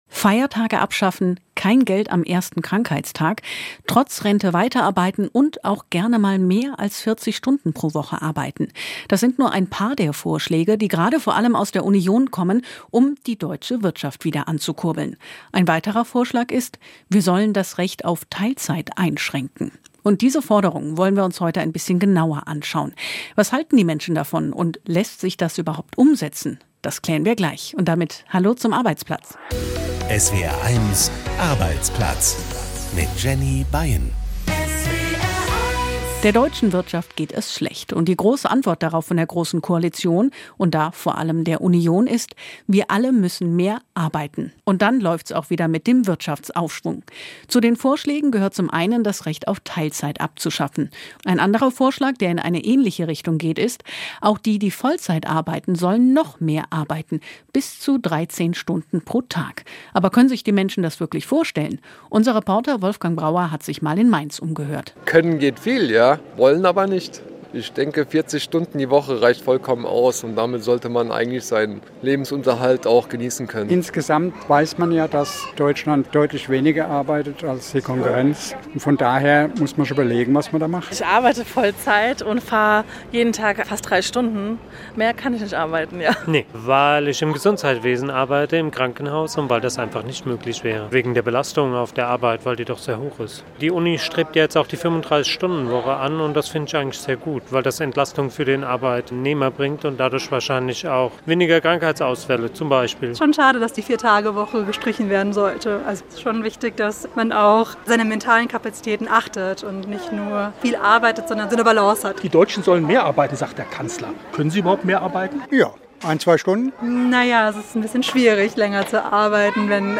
Das Radiomagazin für Arbeitnehmer und Arbeitgeber, für Betroffene und Beobachter, für Eltern und Lehrer, Auszubildende und Ausbilder. Reportagen aus Betrieben, Hintergründe, Meinungen, Urteile und Interviews.